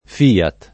FIAT [ f & at ] n. pr. f.